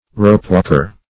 Meaning of ropewalker. ropewalker synonyms, pronunciation, spelling and more from Free Dictionary.
Ropewalker \Rope"walk`er\, n.